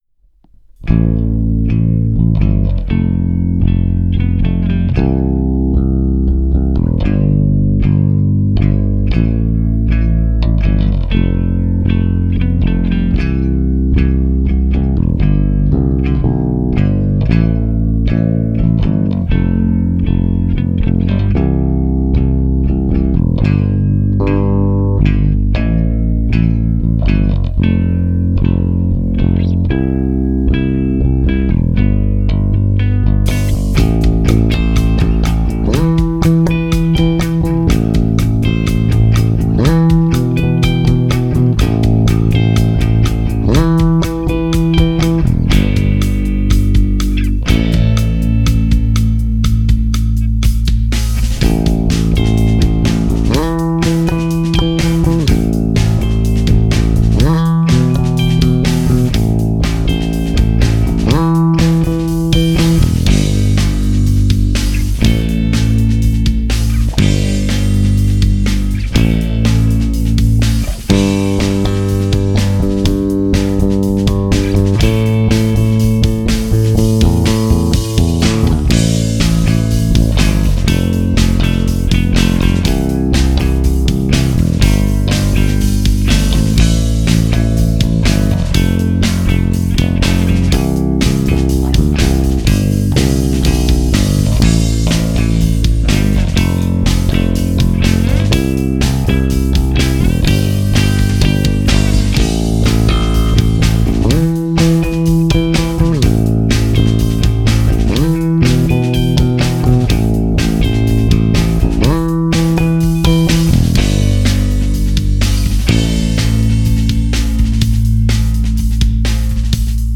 (instrumentale)
Cover / Basse uniquement